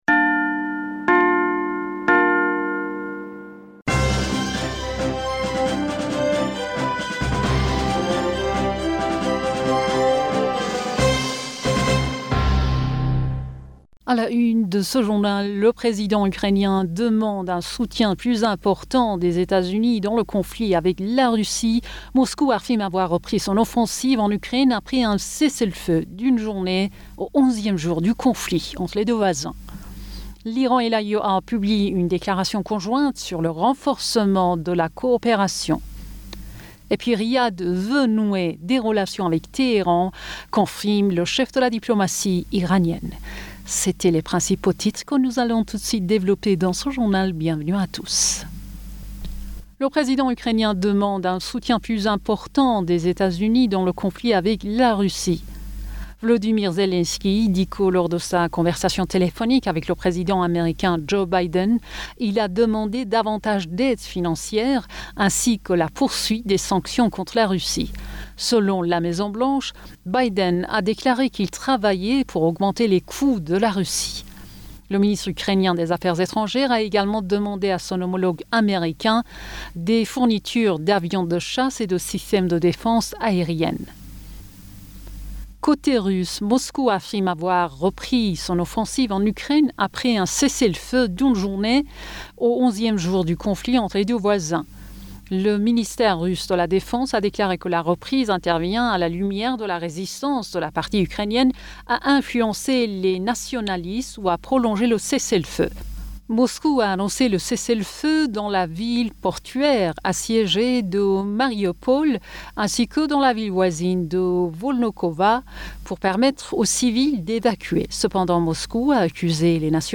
Bulletin d'information Du 06 Mars 2022